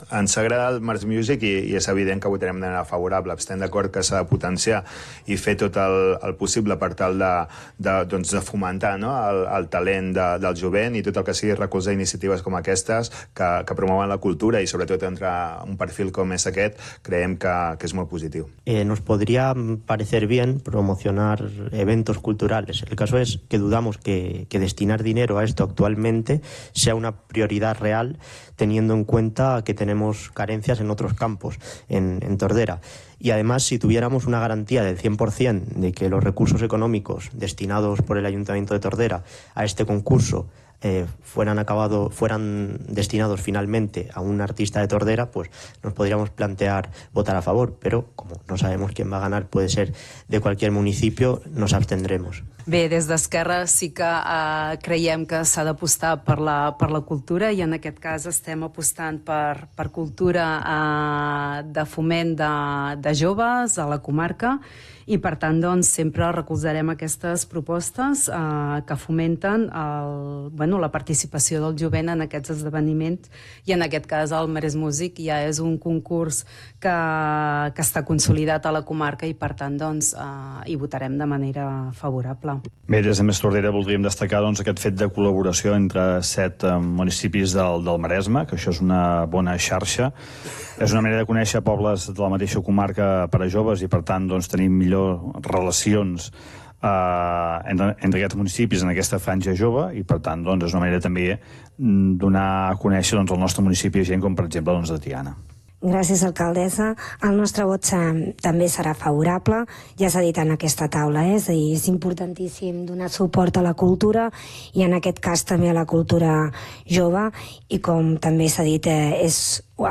Tots els grups municipals van votar favorablement, menys VOX que es van abstenir. Escoltem Salvador Giralt d’En Comú Podem, Eric Pérez de VOX, Marta Paset d’ERC, Xavier Pla de Més Tordera i Toñi Garcia del PSC.